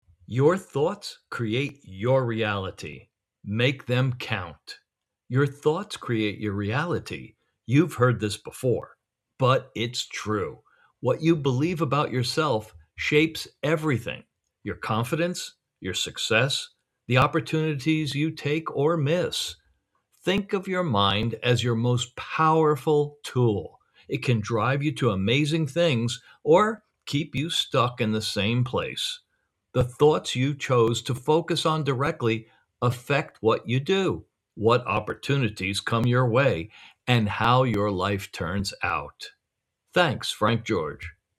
Male
Adult (30-50)
Warm, inviting, friendly, rich, authoritative, narration, clear, technical, commercial, soothing, calm, confident, upbeat, energetic, fun, punchy, trustworthy, credible, e-learning, audiobook, empathetic, Christian, announcer, radio, tv, corporate, educated, medical,
Audiobooks
Narration For Audiobook
All our voice actors have professional broadcast quality recording studios.